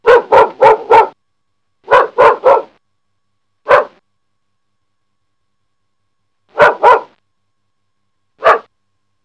A golden retriever who is five years old.
bark.wav